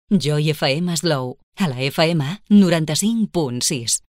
Indicatiu Costa Brava - Girona.
Indicatiu amb la freqüència d'FM.